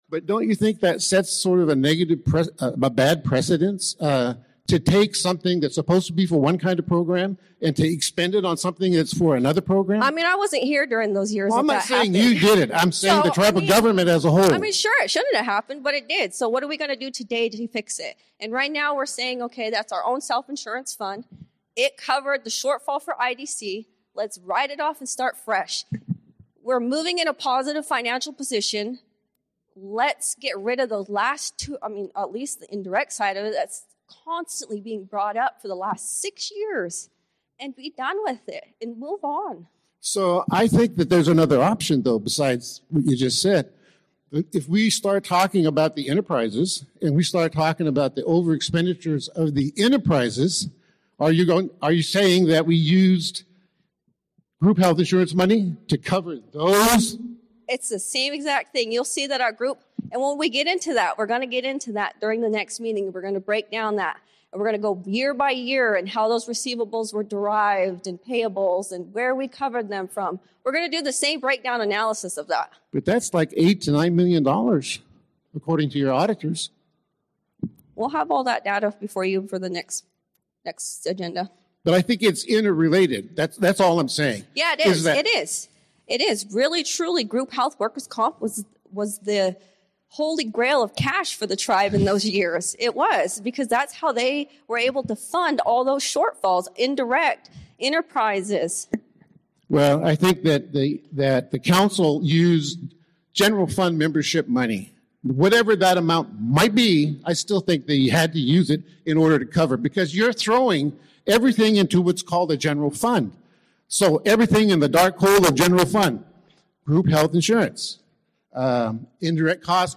This is the 4th hour of the six-hour recording of the Hoopa Tribal General Meeting of 2-21-26.